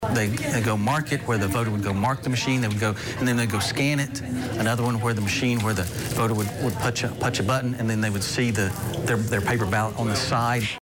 Commission Chair Gary McAlister Told News 5…..